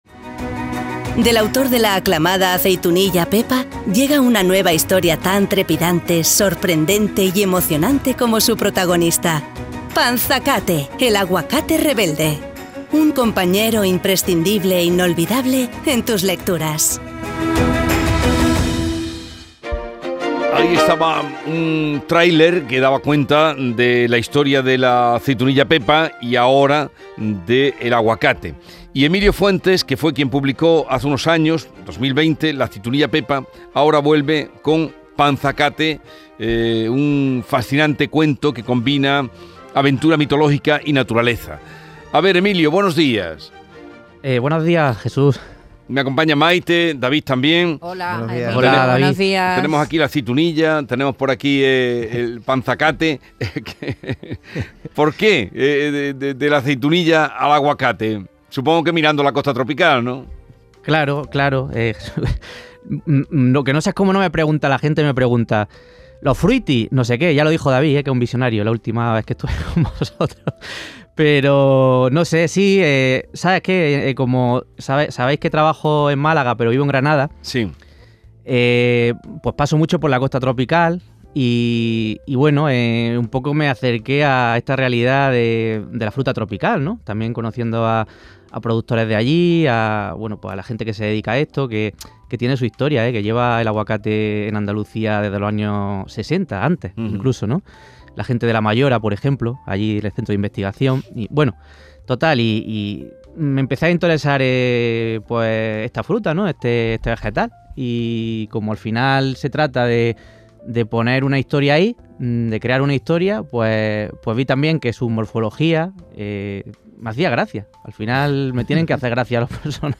A la Mañana de Andalucía de Canal Sur Radio con Jesús Vigorra